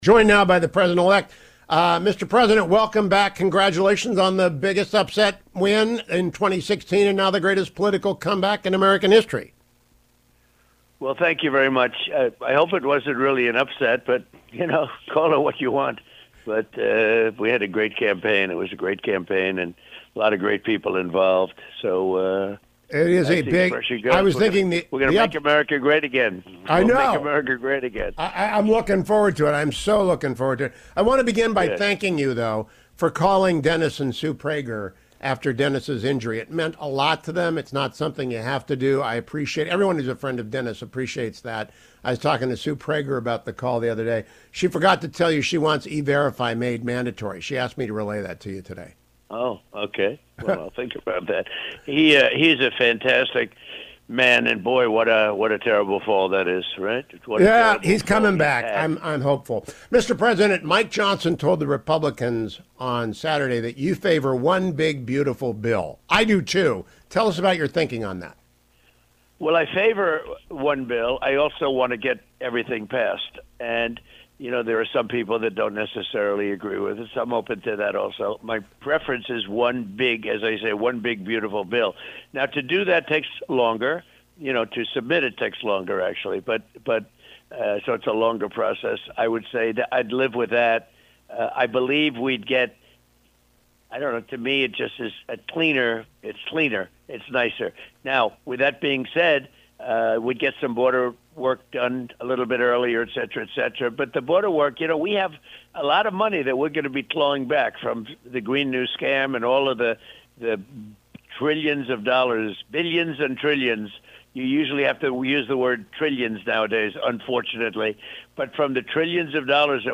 Former and future President Donald Trump joined me this morning: